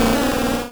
Cri d'Excelangue dans Pokémon Rouge et Bleu.